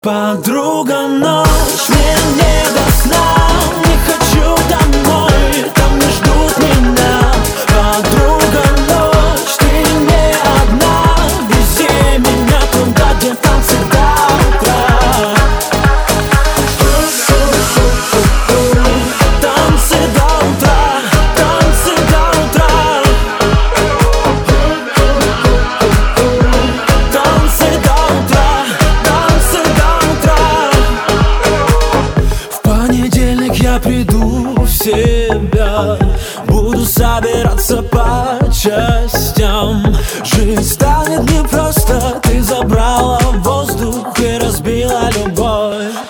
• Качество: 256, Stereo
поп
мужской вокал
заводные
dance
club